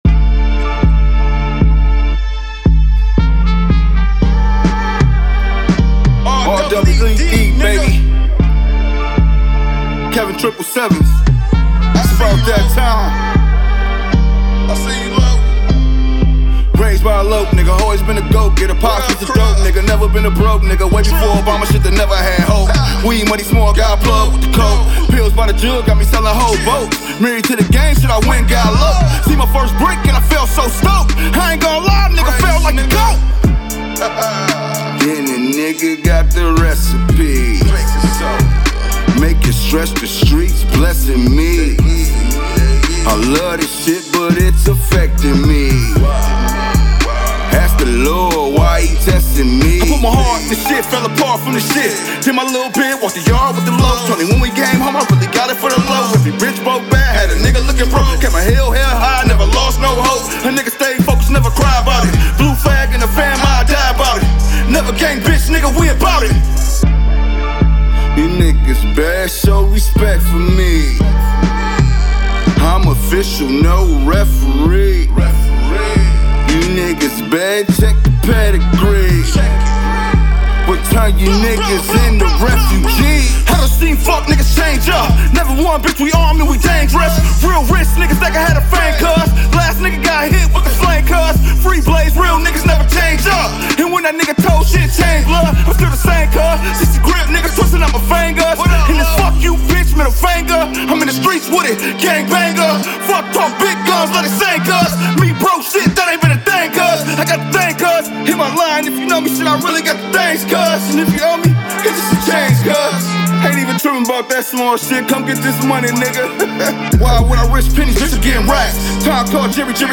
Rap
raw and in your face